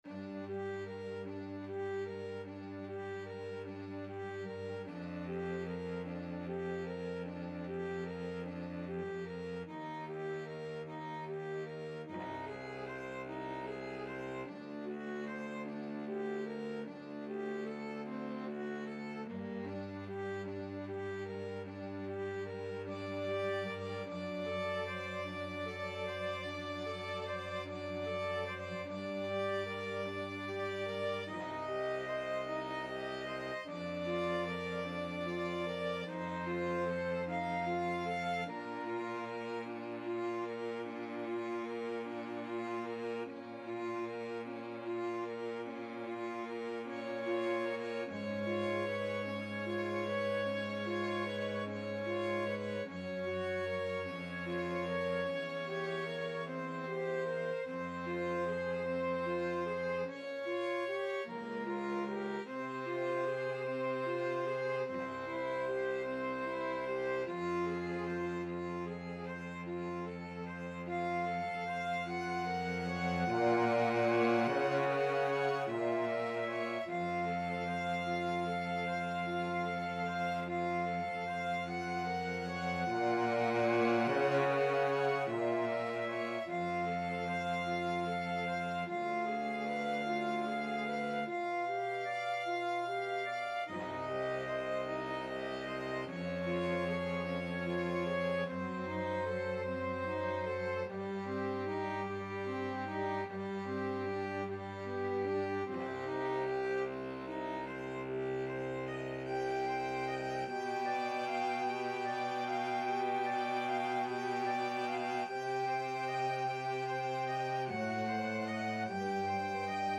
Flute
Alto Saxophone
Euphonium
2/2 (View more 2/2 Music)
Largo